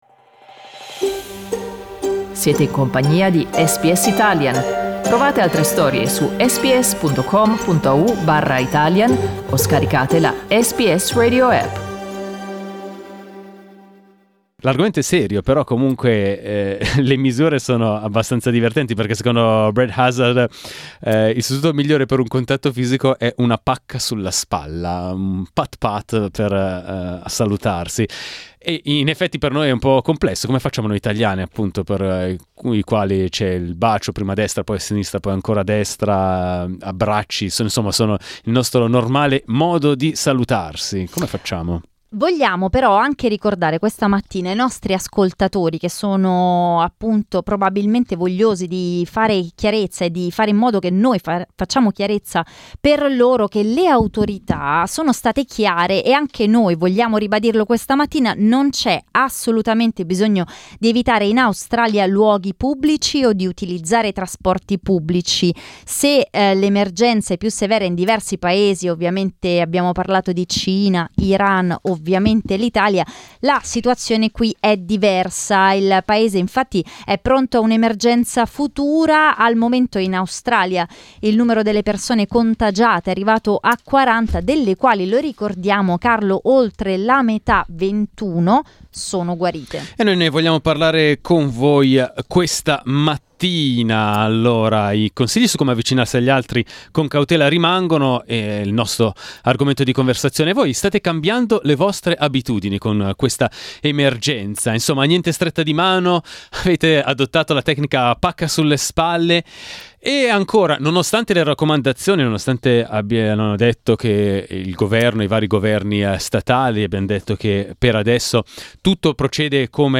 L'emergenza ha limitato la socialità? La parola agli ascoltatori
Noi lo abbiamo chiesto questa mattina ai nostri ascoltatori.